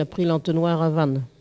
collecte de locutions vernaculaires
Catégorie Locution